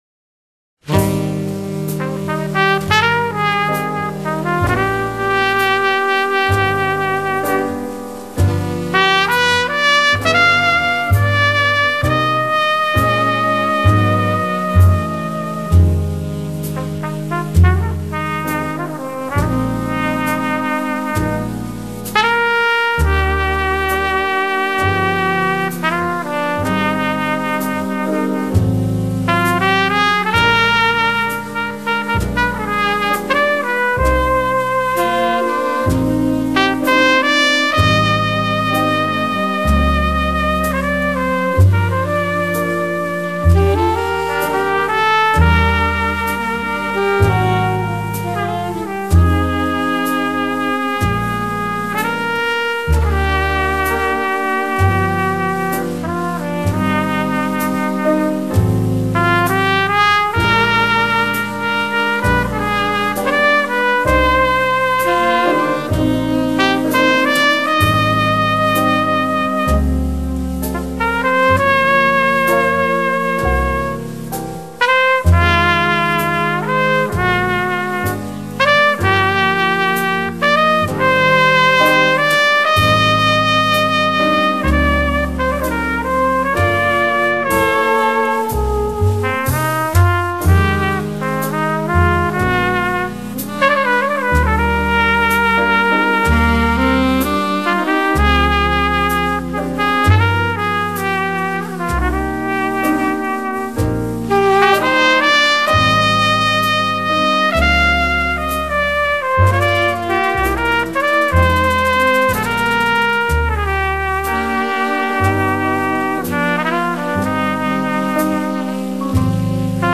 音樂風格︰Jazz, Hard Bop, Soul | 1CD |